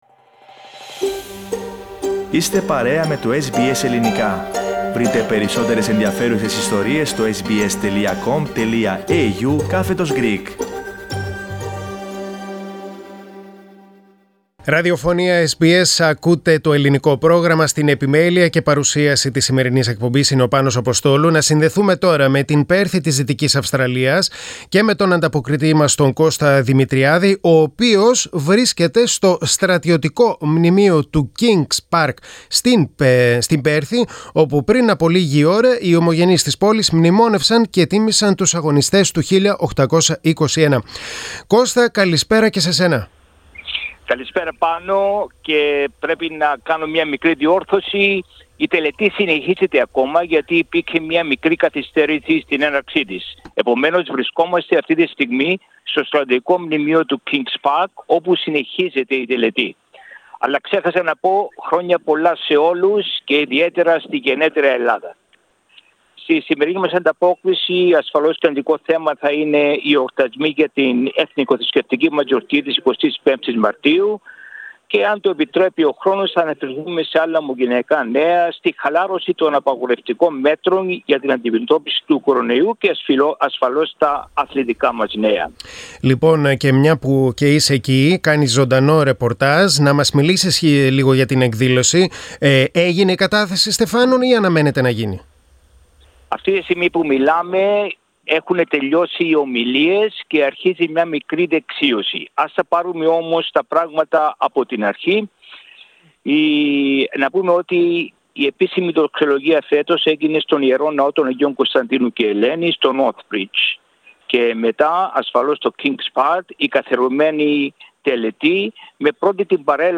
Η εβδομαδιαία ανταπόκριση από την Δυτική Αυστραλία, αυτήν την εβδομάδα από το Στρατιωτικό Μνημείο του Kings Park στην Πέρθη.